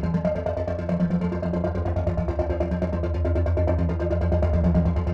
Index of /musicradar/dystopian-drone-samples/Tempo Loops/140bpm
DD_TempoDroneE_140-F.wav